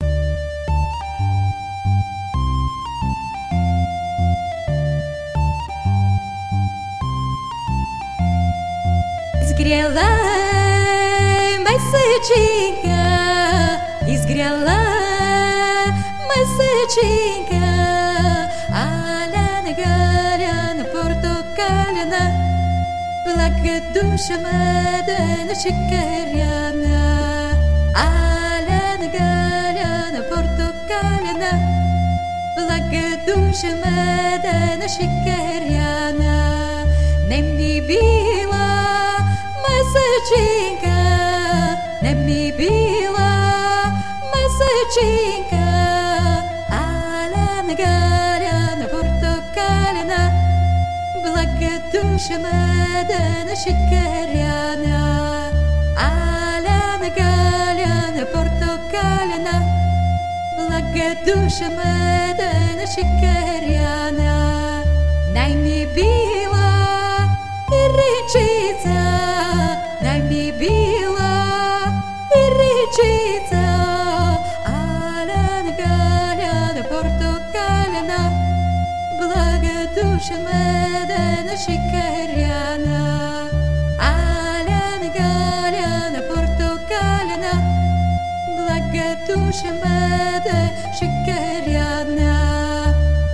Izgrejala mesetchinka - danse chantée (mp3)